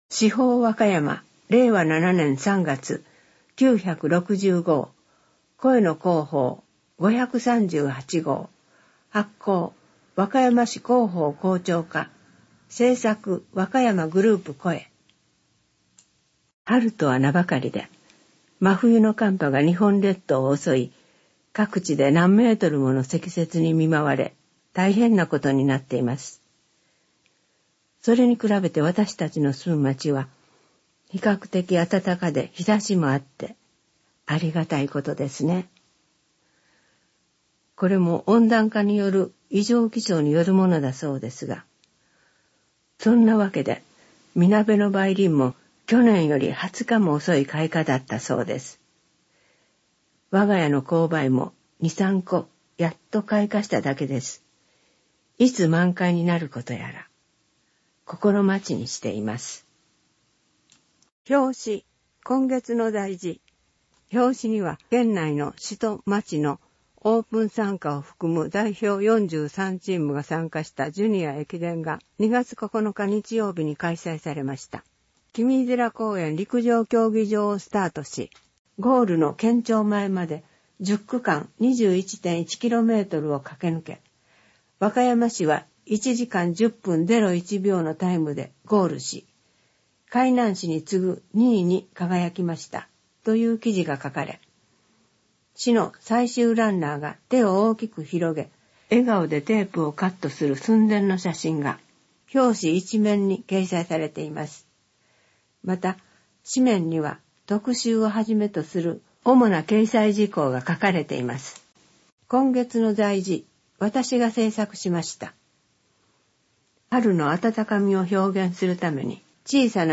3月号（PDF版・テキスト版・音声版） 一括ダウンロード 市報わかやま 令和7年3月号 （PDF 3.8MB） 市報わかやま 令和7年3月号 （TXT 90.0KB） 市報わかやま 令和7年3月号（声の市報） （外部リンク） 分割ダウンロード 表紙 1ページ （PDF 733.5KB） 1ページ （TXT 1.1KB） 特集 西コミュニティセンター 4月13日（日曜日） オープン！